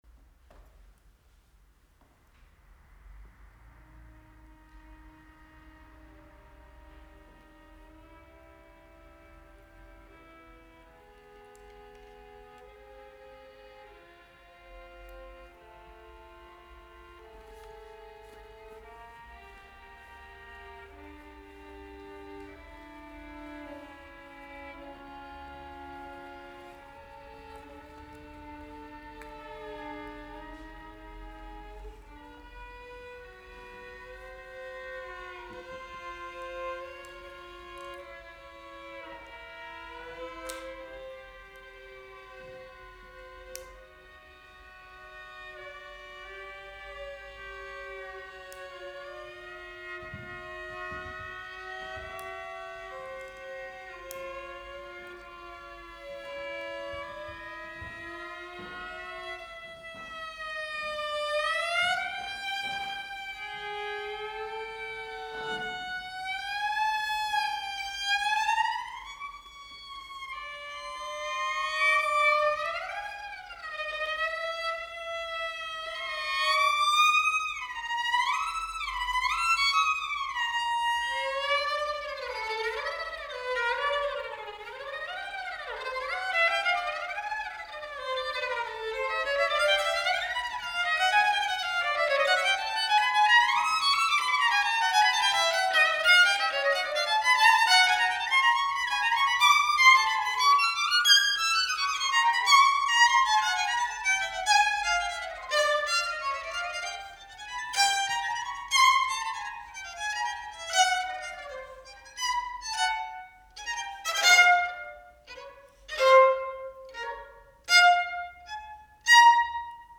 für Geige